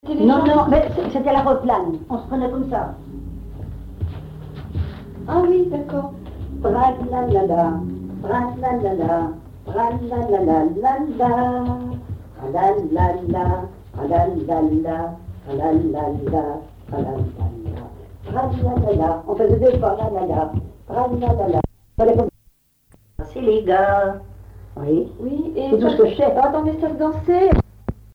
Localisation Cancale (Plus d'informations sur Wikipedia)
Fonction d'après l'analyste danse : aéroplane ;
Genre brève
Catégorie Pièce musicale inédite